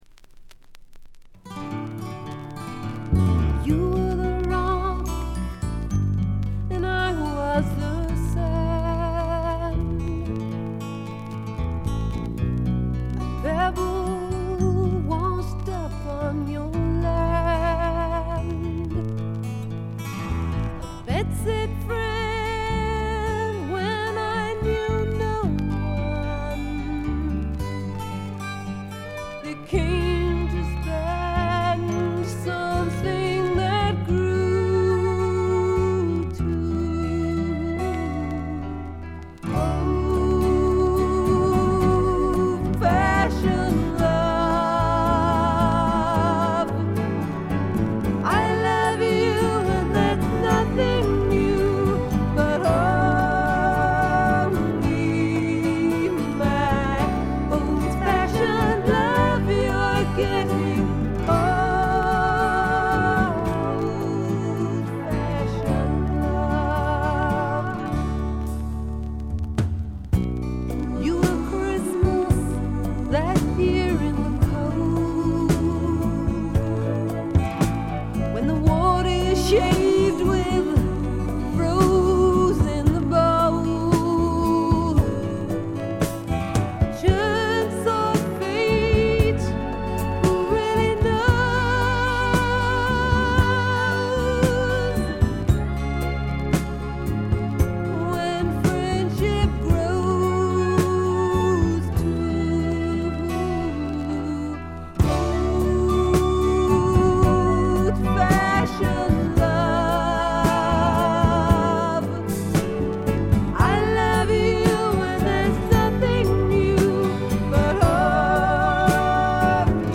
バックグラウンドノイズが常時出ており静音部ではやや目立ちます。
英国の女性シンガー・ソングライター／フォークシンガー。
内容は80sぽさはまったくなく70年代のシンガー・ソングライター黄金期の空気感が全体をおおっていてとても良い感じ。
試聴曲は現品からの取り込み音源です。